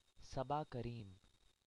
pronunciation (born 14 November 1967) is a former Indian cricketer.